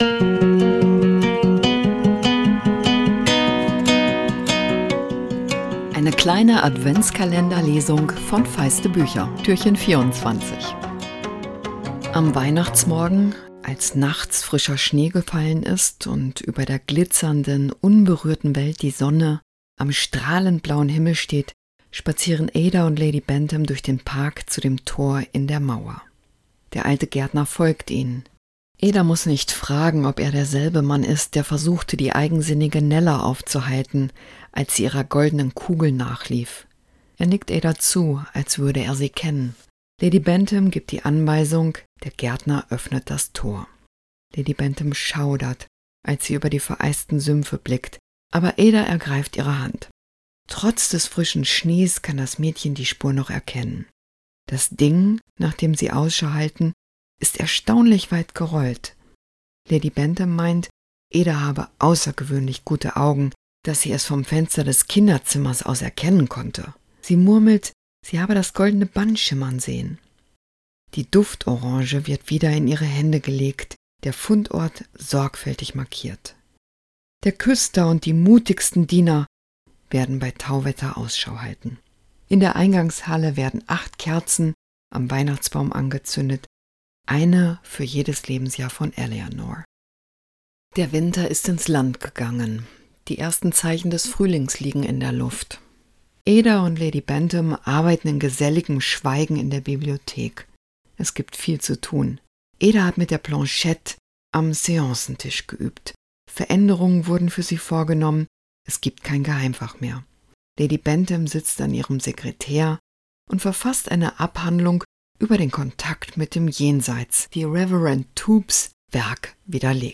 Feiste Bücher 117-24, Adventslesung 2024: "Ada Lark" von Jess Kidd, aus: "Wintergeister"